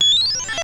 There are four different sounds produced by the game.
QSDefender_AlienMissile.wav